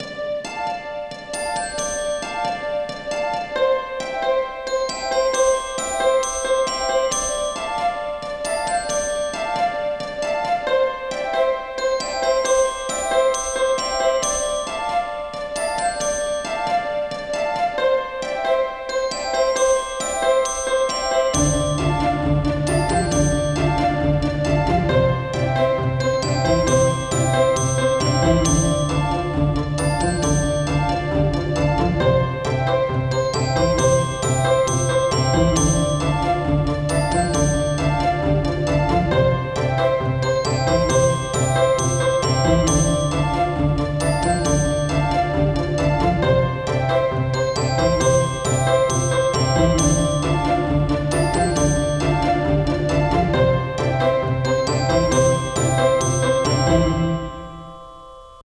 Game Music
Motivational Musics for Kid’s game